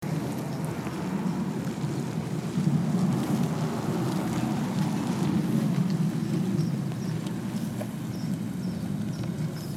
Following the Lesser Long-nosed Bat’s migratory route southward, towards Mexico, on my ArcGIS map, I include a likewise multivalent audio recording from Organ Pipe Cactus National Monument, which houses Tohono O’odham burial sites and other endangered animals such as the Sonoran Pronghorn and Quitobaquito Pupfish.
here, one may vaguely hear bird chirps, footsteps, a trickle of water, and some kind of traffic. Only the expert ear would be able to identify the specific sound of steamrollers cruising along Highway 85, having just left the site of border wall construction near Lukeville, Arizona, where they likely aided the hasty removal of local flora and fauna.